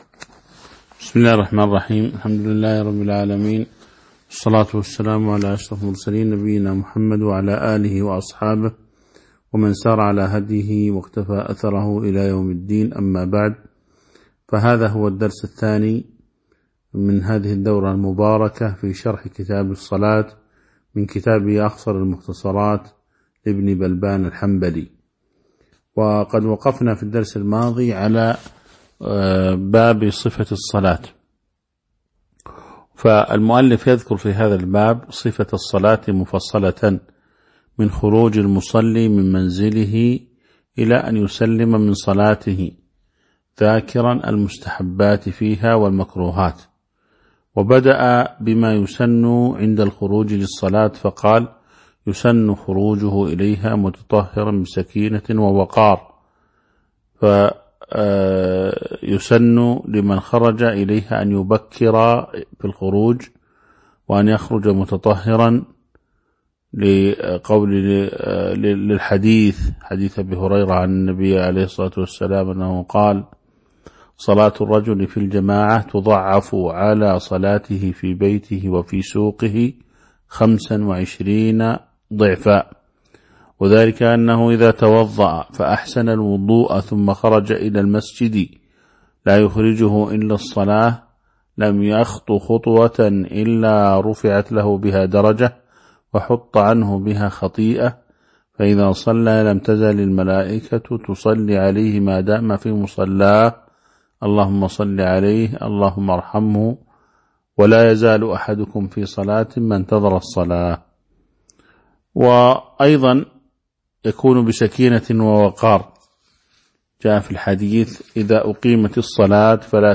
تاريخ النشر ٢٩ جمادى الأولى ١٤٤٢ هـ المكان: المسجد النبوي الشيخ